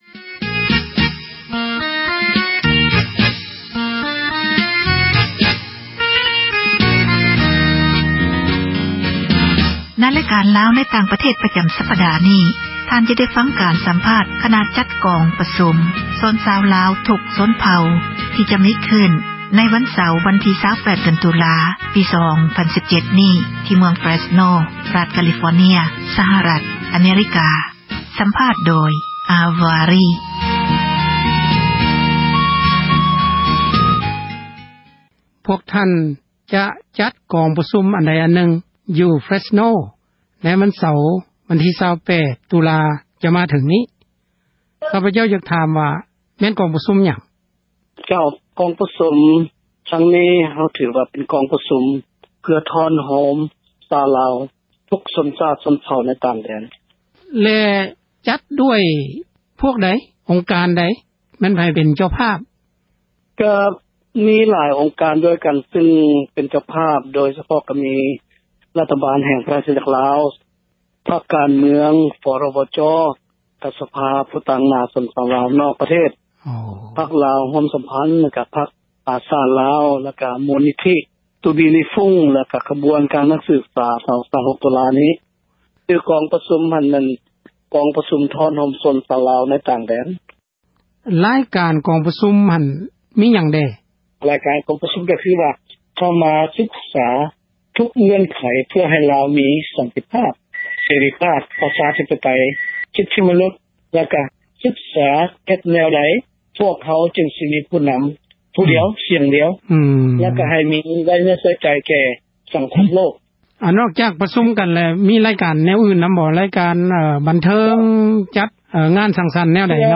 ສັມພາດຄນະຈັດກອງປະຊຸມ ຊົນຊາວລາວ